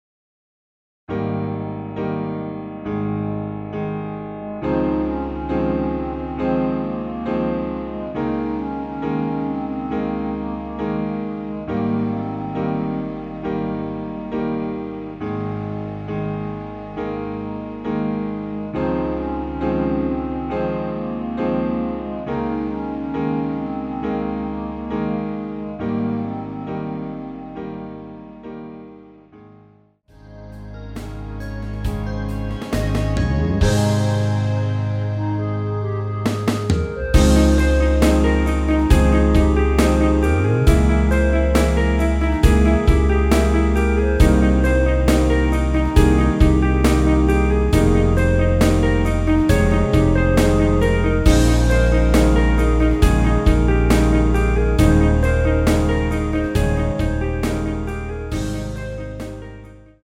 전주 없이 시작하는곡이라 전주 1마디 만들어놓았습니다.(미리듣기 참조)
원키에서(-4)내린 멜로디 포함된 MR입니다.
Ab
앞부분30초, 뒷부분30초씩 편집해서 올려 드리고 있습니다.
중간에 음이 끈어지고 다시 나오는 이유는